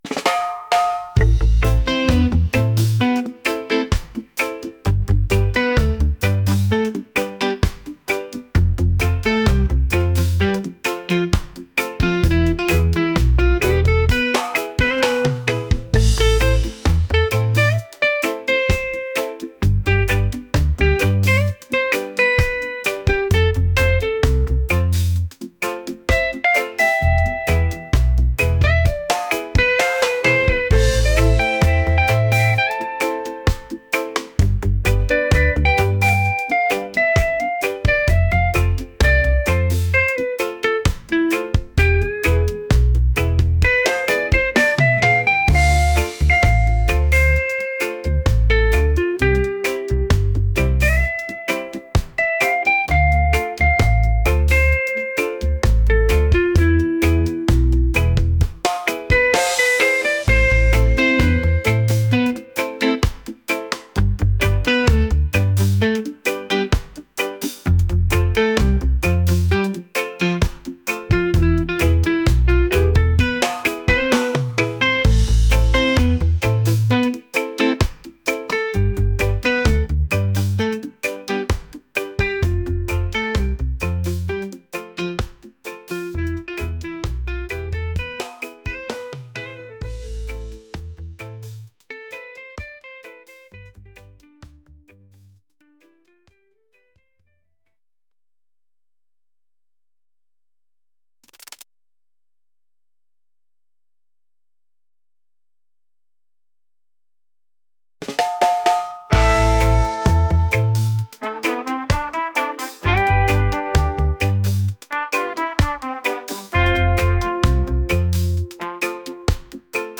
soul | upbeat | reggae